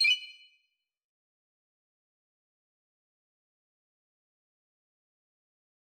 error_style_4_006.wav